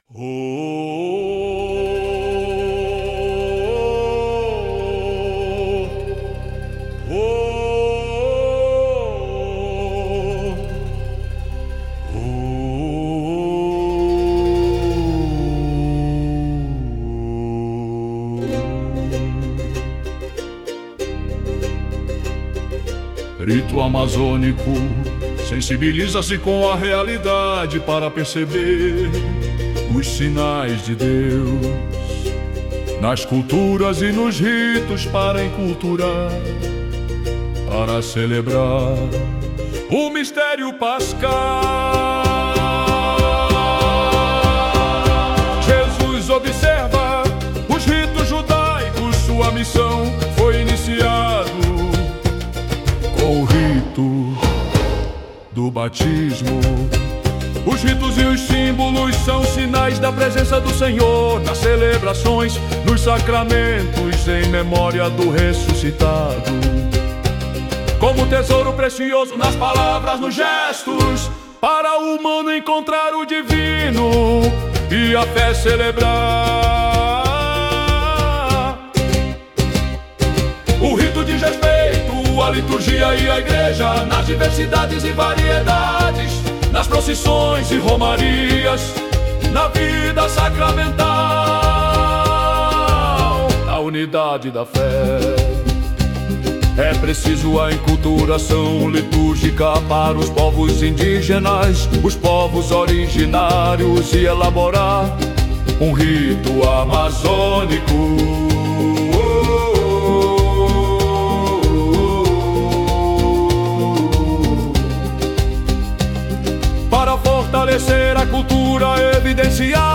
A melodia e as vozes do hino foram geradas com o apoio de inteligência artificial, em uma combinação simbólica entre tradição e inovação — um gesto que reflete o diálogo entre a sabedoria ancestral dos povos e as novas linguagens do tempo presente.